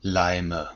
Ääntäminen
Ääntäminen Tuntematon aksentti: IPA: /ˈlaɪ̯mə/ Haettu sana löytyi näillä lähdekielillä: saksa Käännöksiä ei löytynyt valitulle kohdekielelle. Leime on sanan Leim monikko.